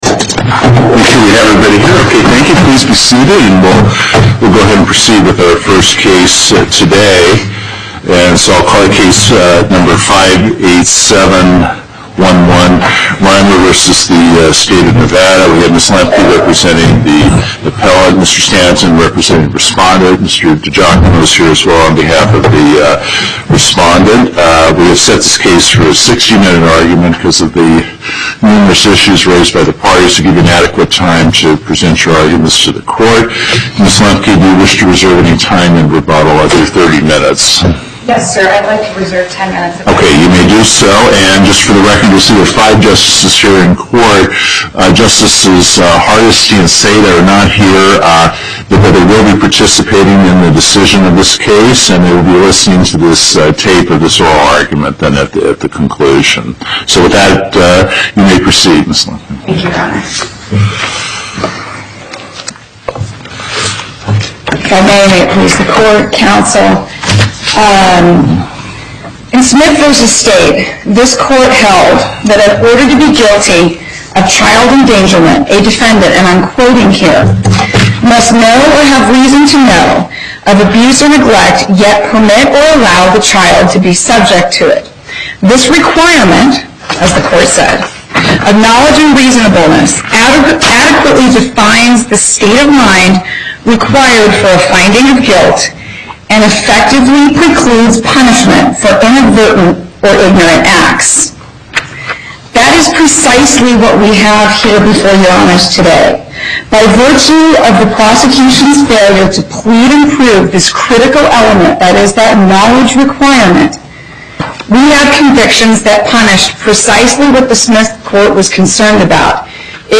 Location: Las Vegas Before the En Banc Court, Chief Justice Gibbons Presiding
as counsel for the Appellant
as counsel for the Respondent